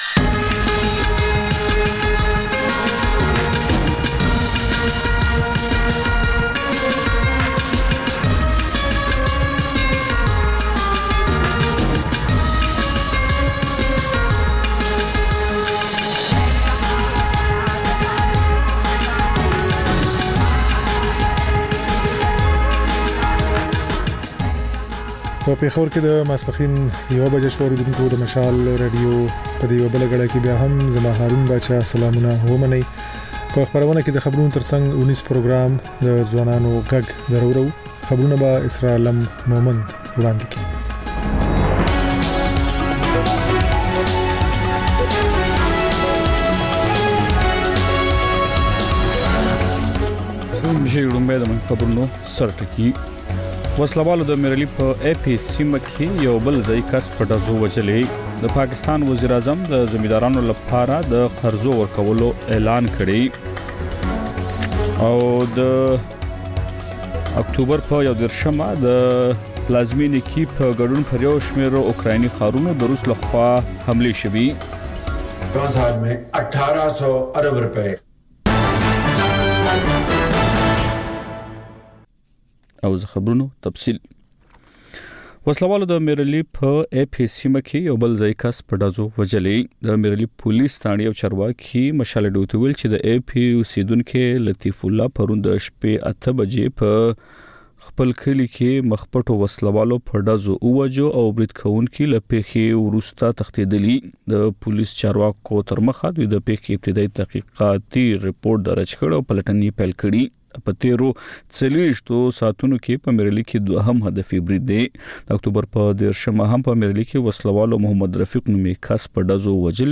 د مشال راډیو لومړۍ ماسپښينۍ خپرونه. په دې خپرونه کې تر خبرونو وروسته بېلا بېل رپورټونه، شننې، مرکې خپرېږي. ورسره یوه اوونیزه خپرونه هم خپرېږي.